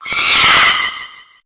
Cri_0885_EB.ogg